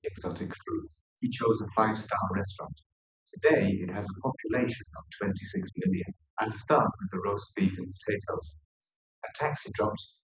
Simulated recordings with 4cm microphone spacing and 380ms reverberation time